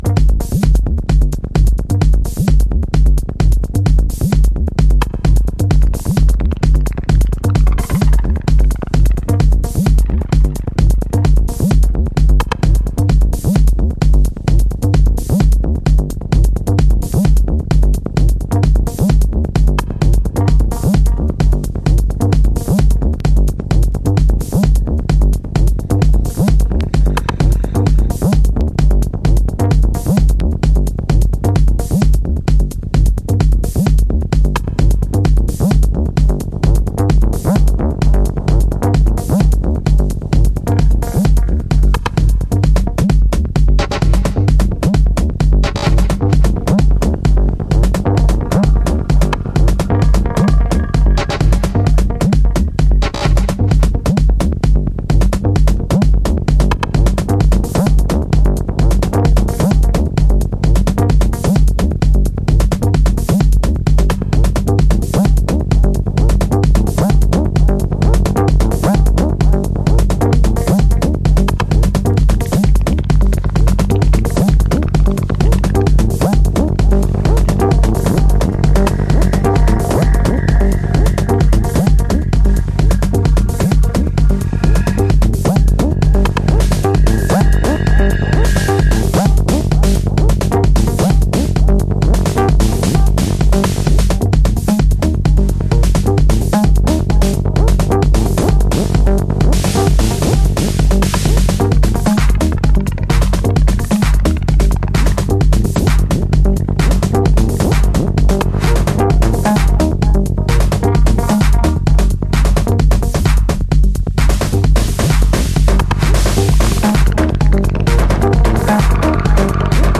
House / Techno
両者の持ち味が活かされた、シンプル&ハードなテクノトラックス。